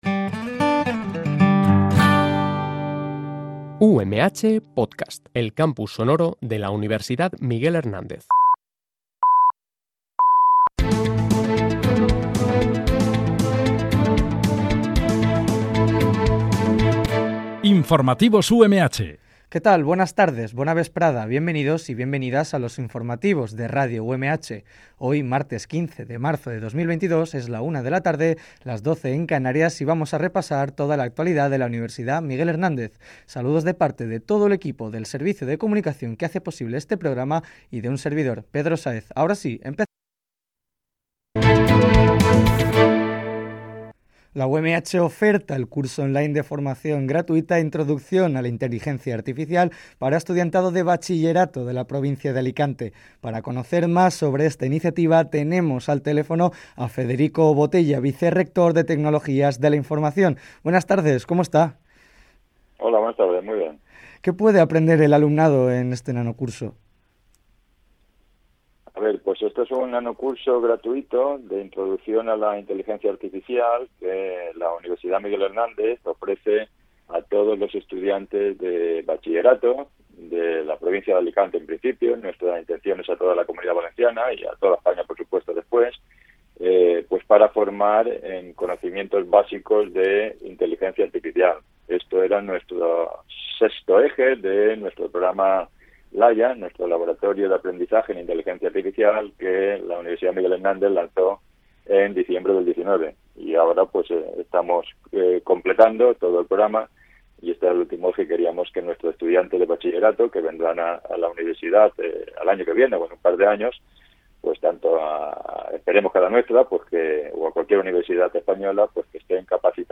Este programa de noticias se emite de lunes a viernes, de 13.00 a 13.10 h